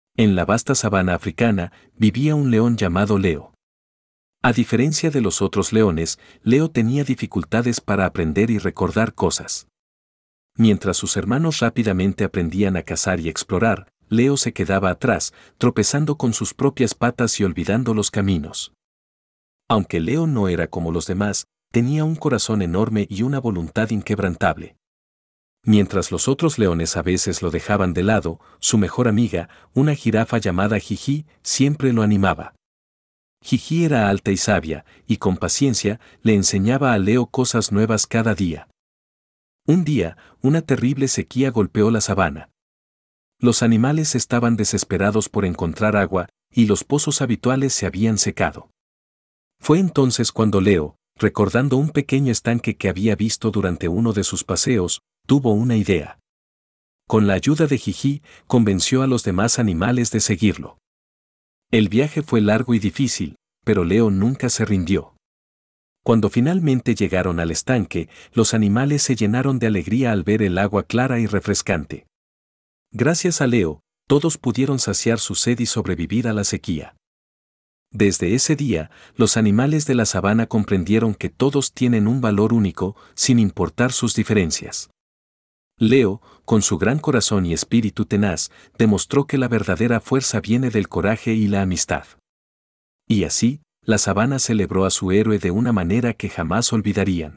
Audio cuento